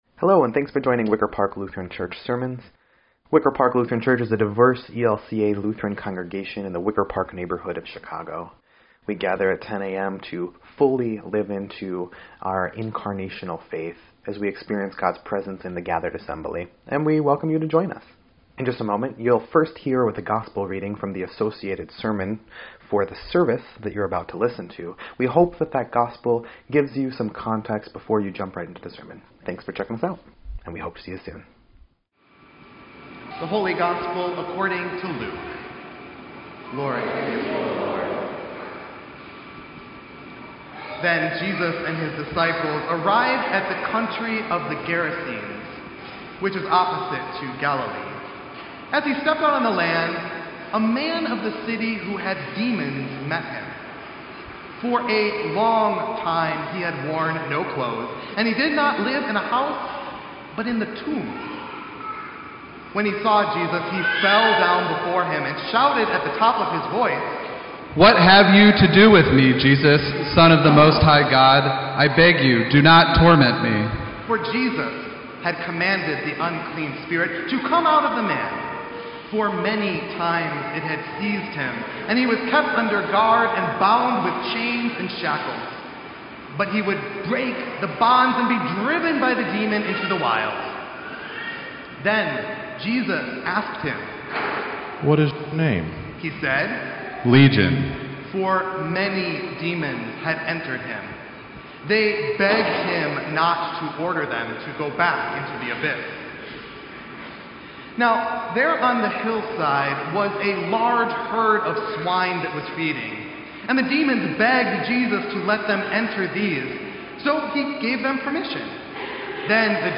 Second Sunday After Pentecost